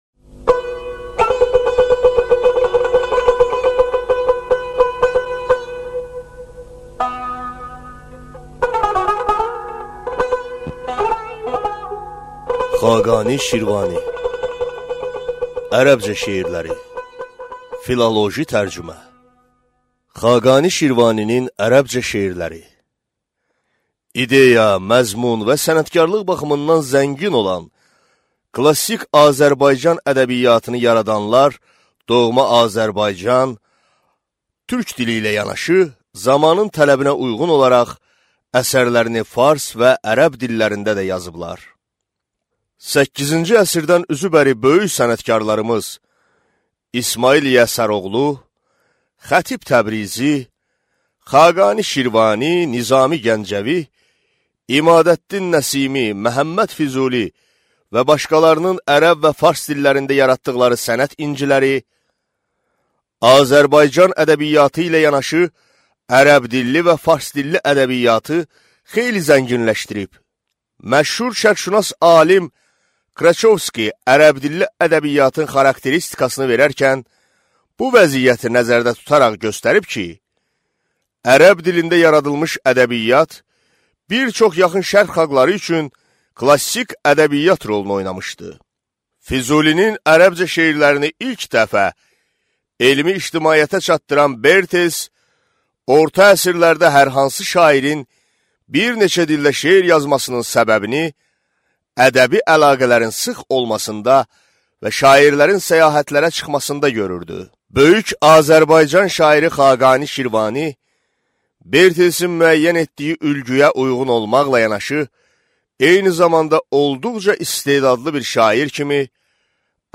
Аудиокнига Xaqani Şirvaninin ərəbcə şerləri | Библиотека аудиокниг